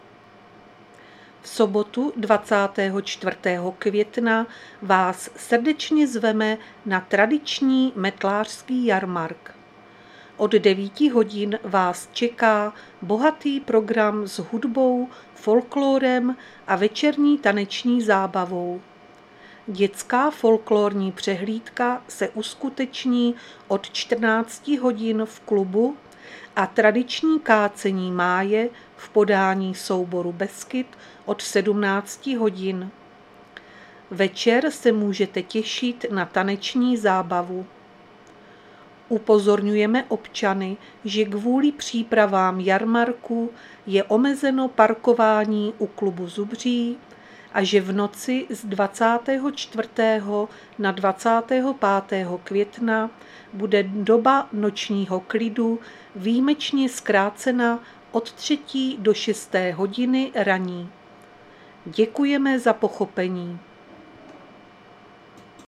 Záznam hlášení místního rozhlasu 22.5.2025
Zařazení: Rozhlas